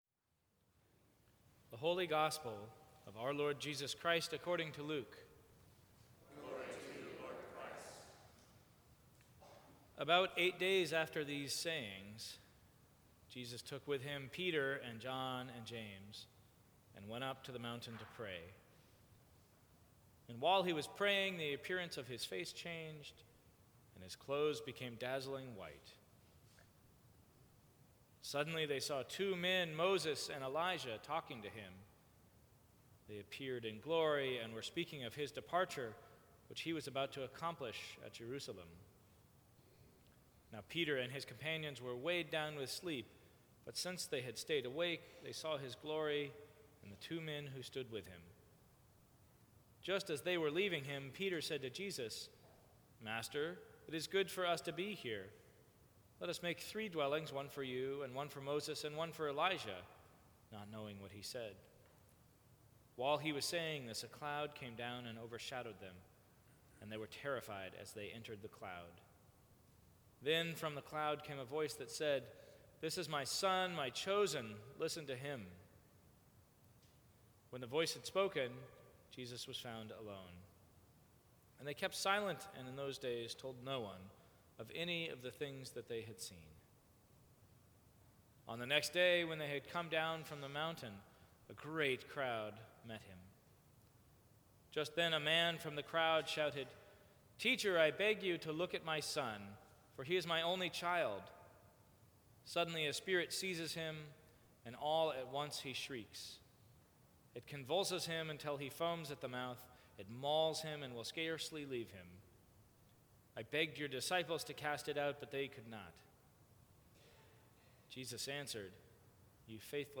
Sermons from St. Cross Episcopal Church Astounded by God Feb 08 2016 | 00:15:33 1x 00:00 / 00:15:33 Subscribe Share Apple Podcasts Spotify Overcast RSS Feed Share Link Embed